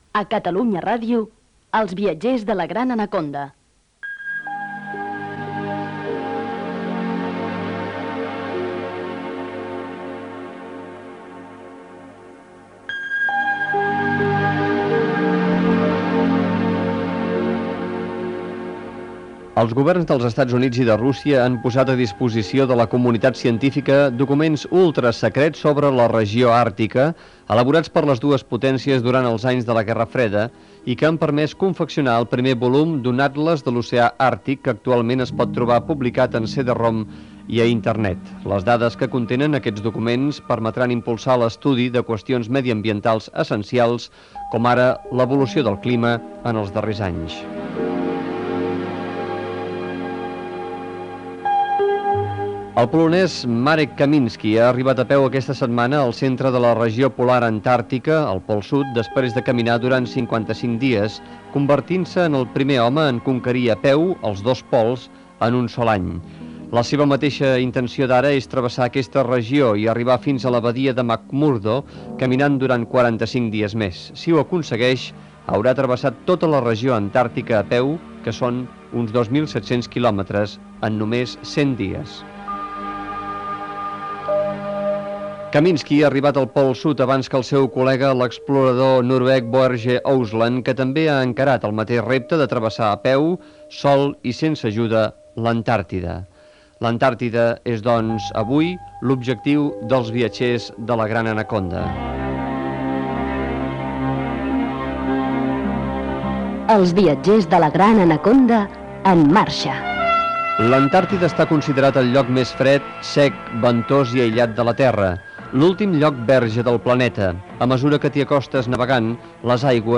0774b116d91795c65e622c698e11ecd7b847703d.mp3 Títol Catalunya Ràdio Emissora Catalunya Ràdio Cadena Catalunya Ràdio Titularitat Pública estatal Nom programa Els viatgers de la gran Anaconda Descripció Indicatiu del programa, espai dedicat a l'Antàrtida amb una connexió amb la goleta Rael que s'hi apropa.